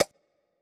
Click (10).wav